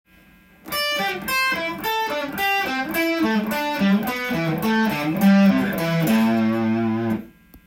【5度で動くマイナーペンタトニックスケール】
②は①の逆になっているので1弦からスタートし
6弦に戻るパターンです。